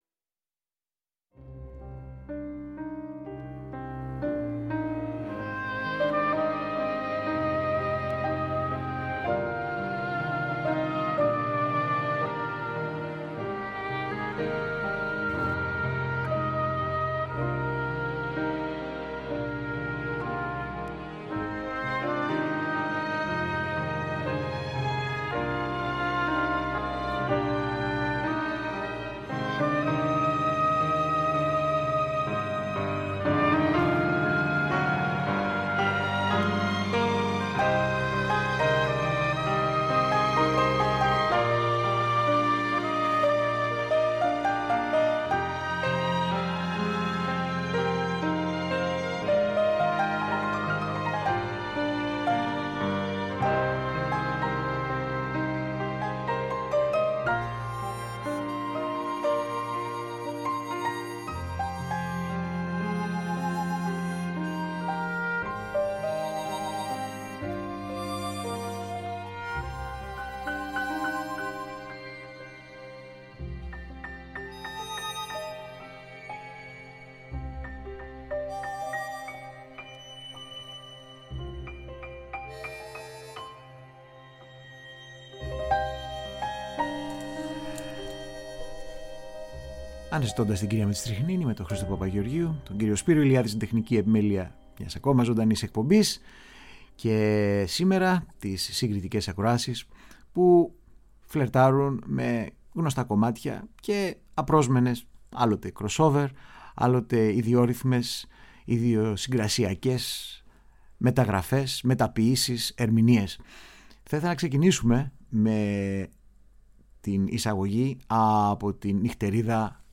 Συγκριτικές ακροάσεις σύντομων κομματιών «χαρακτήρα» σε ιδιαίτερες ερμηνείες και ενίοτε cross-over μεταγραφές, Μέρος 3ο